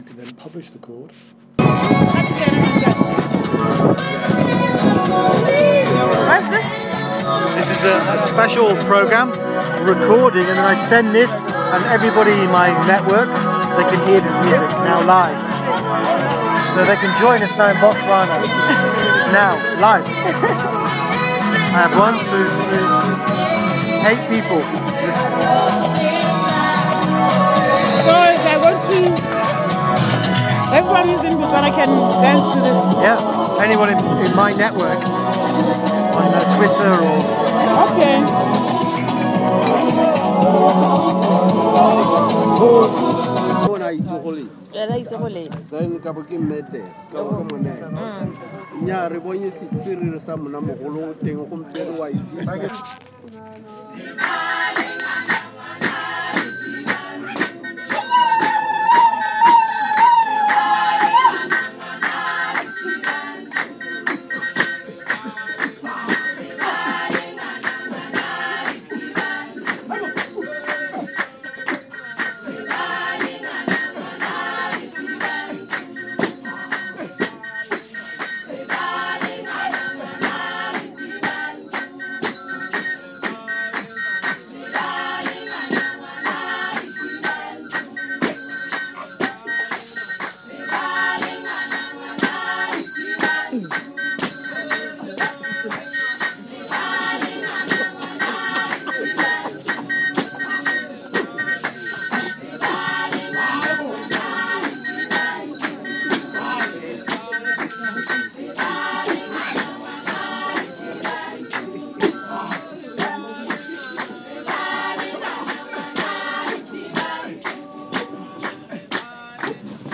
This was one of a series of events held across the globe to celebrate the importance of Tourism, The worlds largest business. This event was organdies organdies by the Tourism department of the City council and included a wide range of events including Traditional Dance group from Francistown.